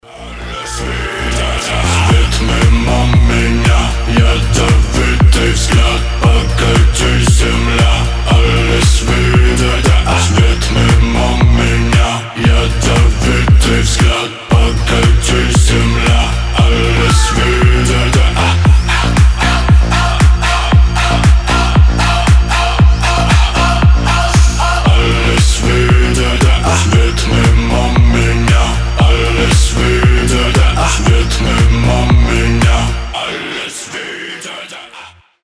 • Качество: 320, Stereo
house